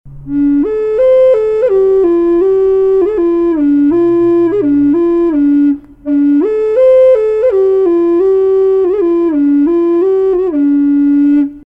Пимак D (low)
Пимак D (low) Тональность: D
Проста в исполнении, но имеет вполне достойное звучание.